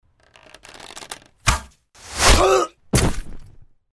Звуки лука, стрел
Выстрел из лука, полет стрелы и точное попадание